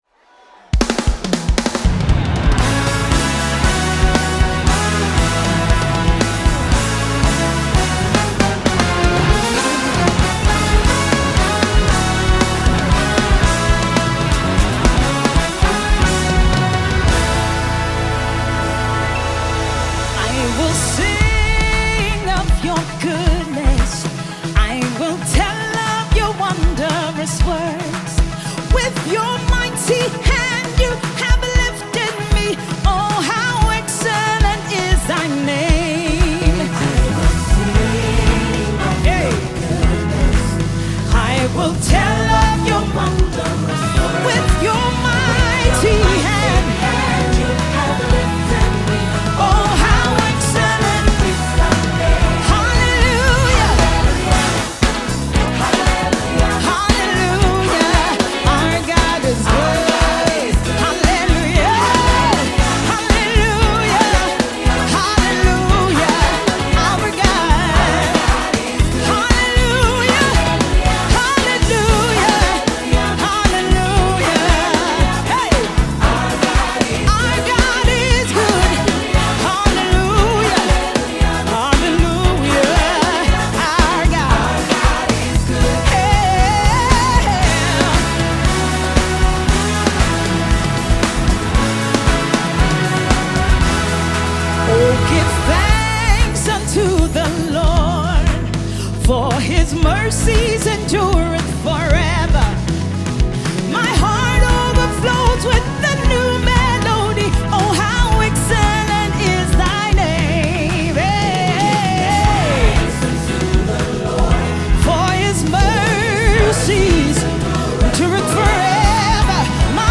South African Gospel
Genre: Gospel/Christian.